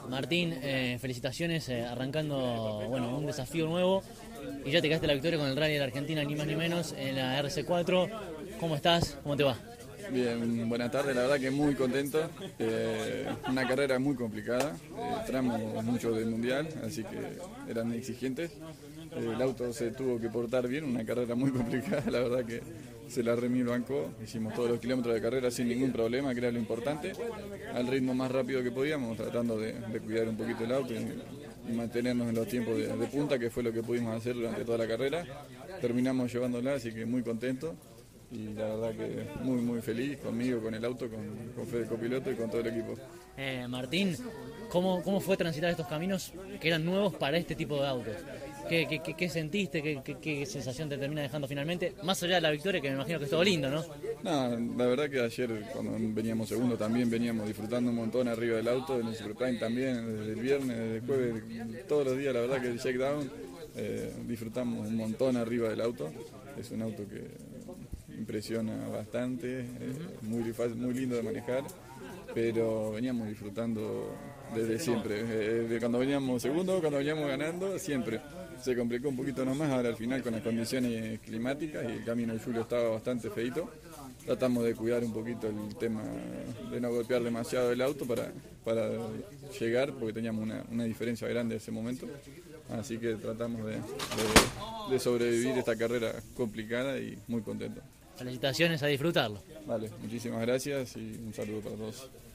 El Rally de la Argentina 2023, que se realizó con Mina Clavero como epicentro, fue cobertura de CÓRDOBA COMPETICIÓN y, allí, dialogamos con todos los protagonistas que se quedaron con el triunfo.